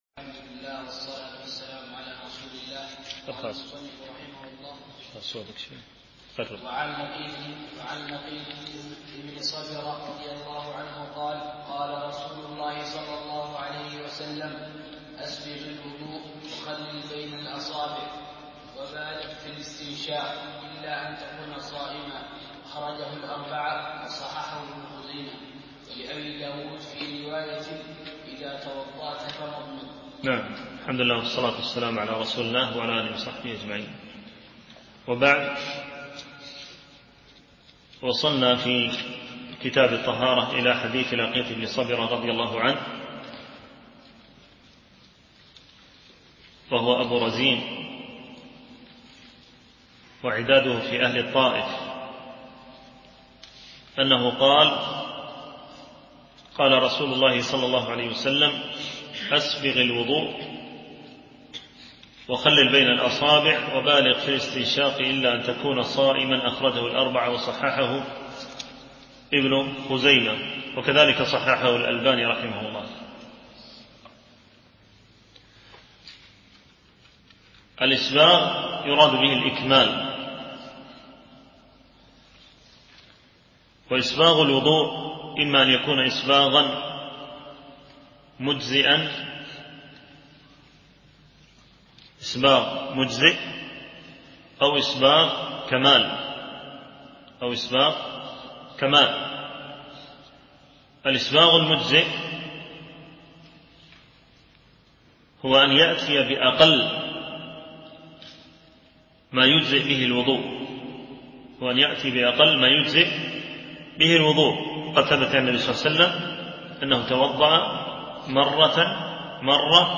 شرح كتاب بلوغ المرام من أدلة الأحكام - الدرس 7 (كتاب الطهارة، الحديث 36-44)
MP3 Mono 16kHz 32Kbps (CBR)